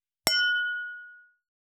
327ガラスのグラス,ウイスキー,コップ,食器,テーブル,チーン,カラン,キン,コーン,チリリン,カチン,チャリーン,クラン,カチャン,クリン,シャリン,チキン,コチン,カチコチ,チリチリ,シャキン,
コップ